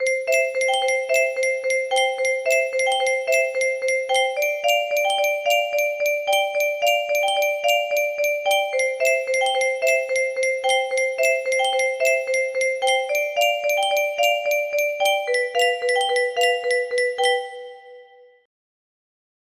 Bell sound music box melody